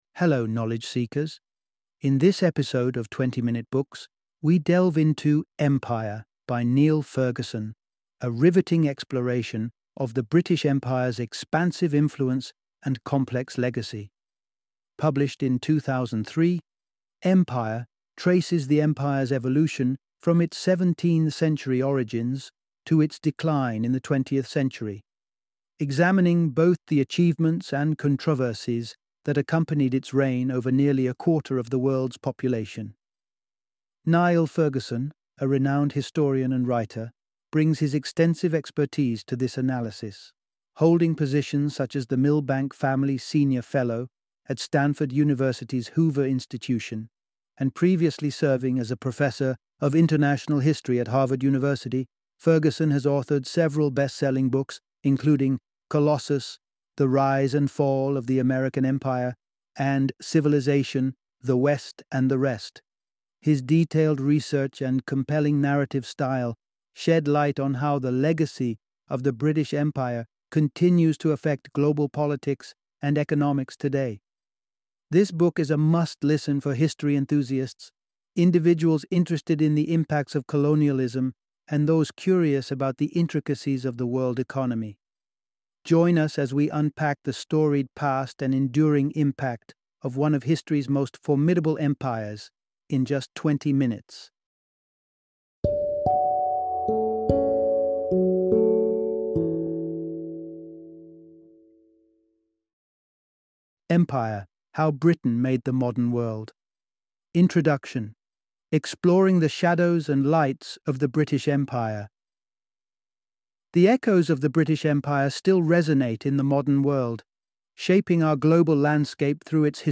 Empire - Audiobook Summary